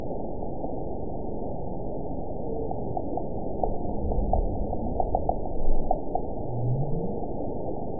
event 922067 date 12/26/24 time 04:50:57 GMT (11 months, 1 week ago) score 9.49 location TSS-AB03 detected by nrw target species NRW annotations +NRW Spectrogram: Frequency (kHz) vs. Time (s) audio not available .wav